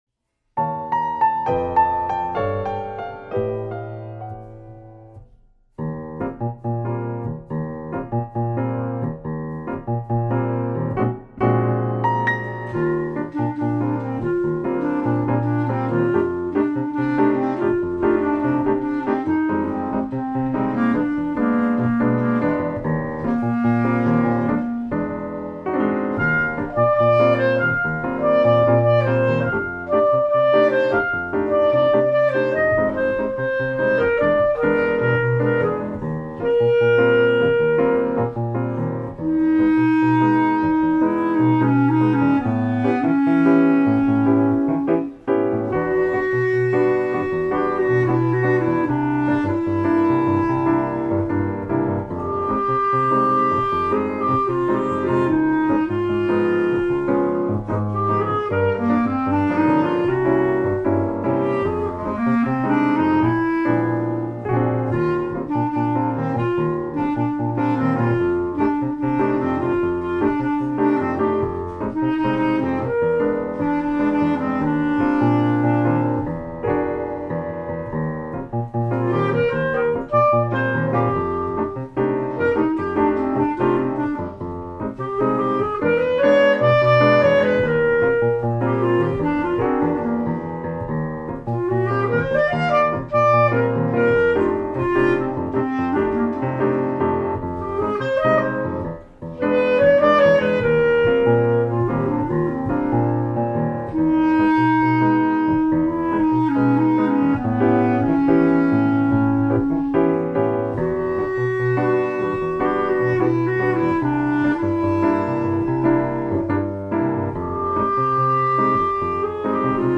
CD音源 ピアノ生演奏。ダンパの音も入るくらい生のピアノの音が収録されています。
楽器 Buffet Crampon Festival
録音機材 Olympus LS-10
録音場所 カラオケ